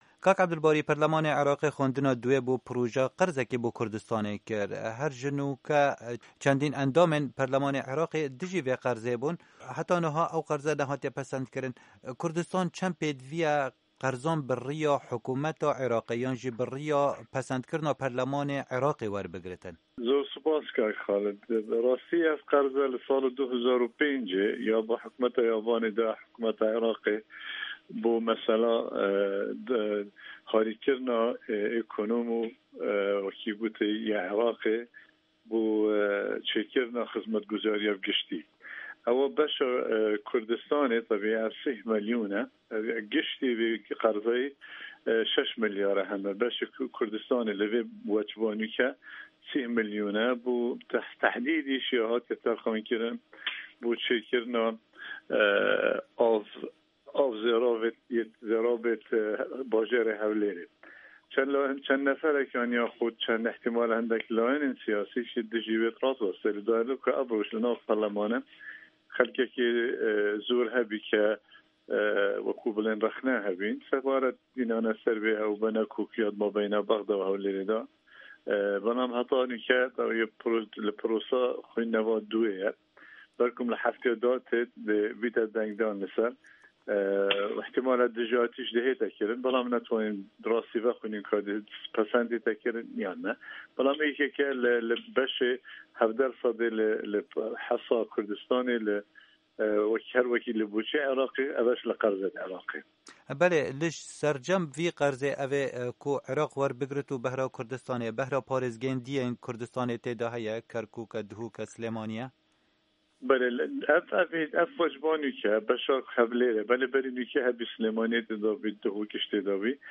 Hevpeyvin digel Ebdulbarî Zêbar